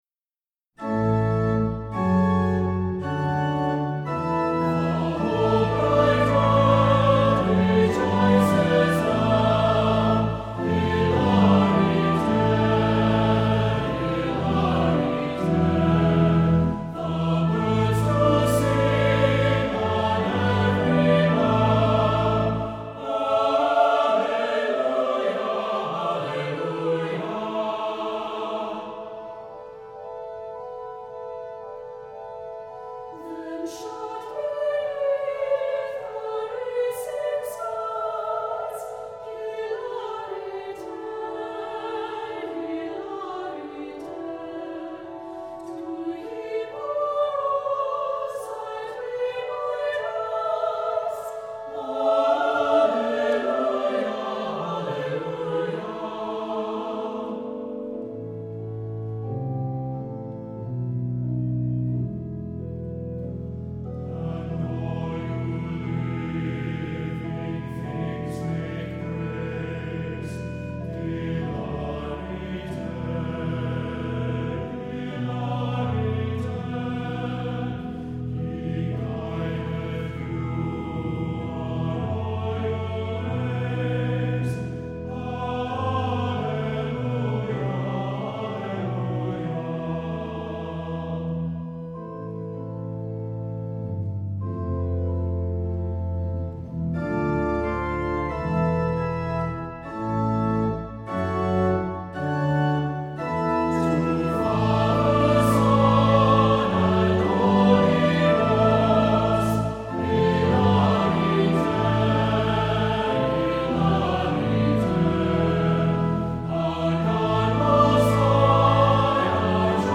Voicing: SAB and Organ